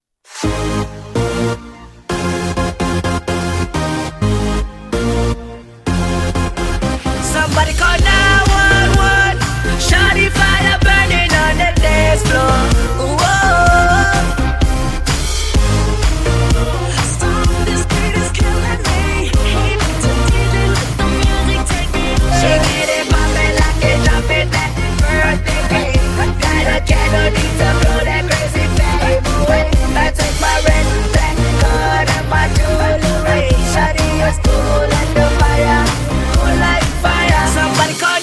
That was ugly.